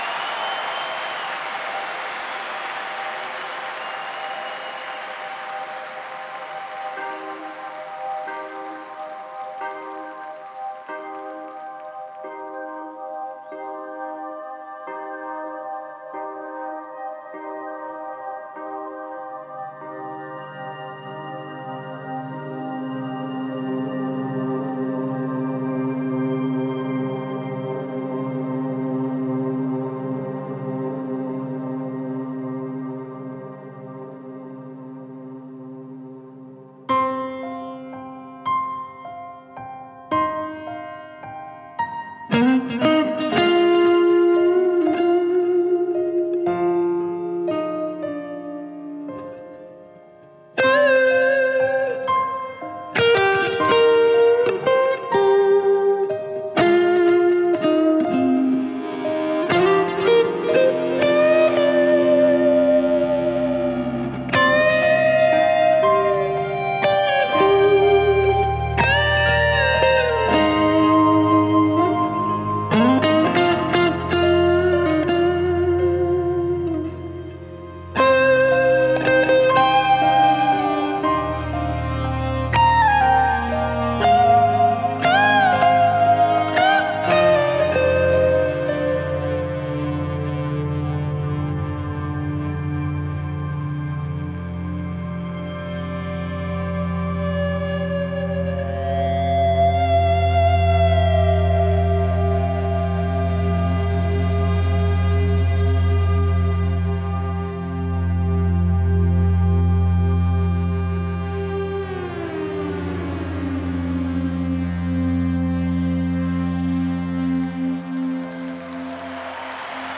Жанр: Rock
Стиль: Prog Rock
Издание на двух CD представляет 23 трека с этого тура.